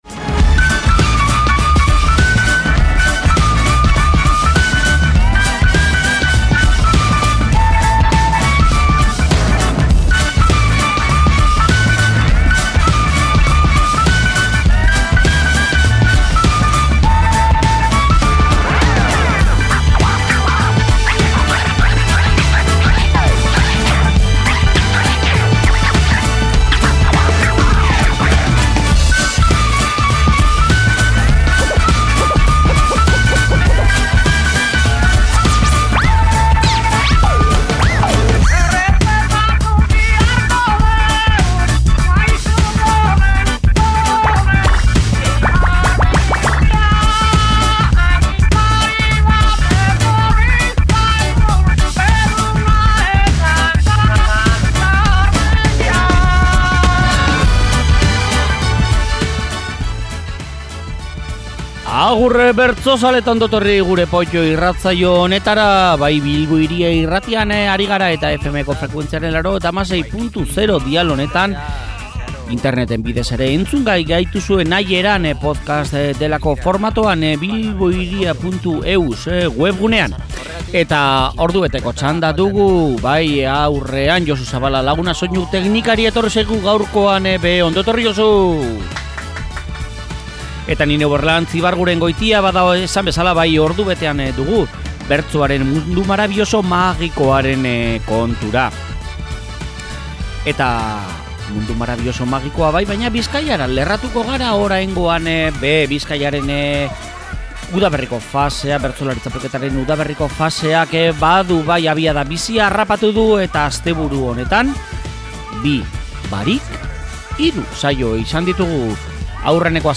Bizkaiko Bertsolari Txapelketaren udaberriko faseak aurrera darrai, eta asteburuan hiru saio izan dira: zapatuan, Otxandion; eta, domekan saio bikoitza (goiz eta arratsaldez), Ondarrun. Hiru saio horietan izandako bertso ale preziatuenak ekarri dituzte gaurko saiora